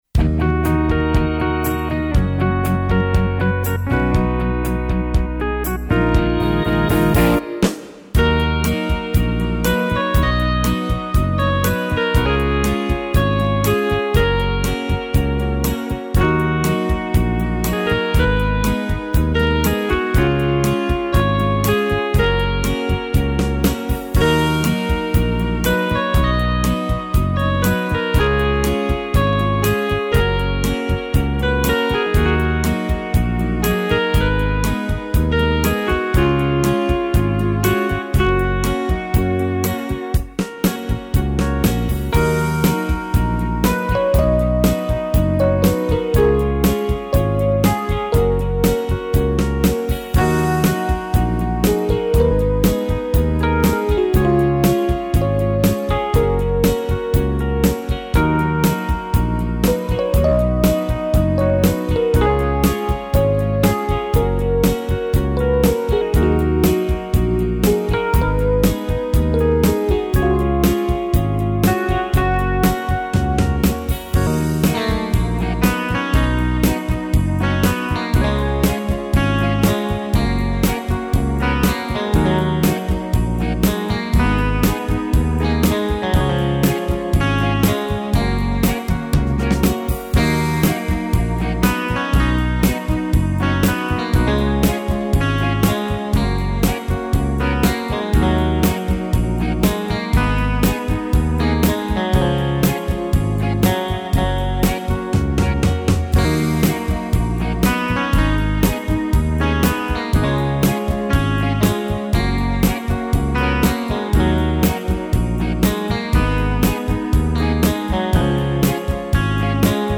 Compositeur interprète, travaille sur Yamaha, avec synthé, séquenceur 2x16 pistes et studio de mixage - Mastering Audio.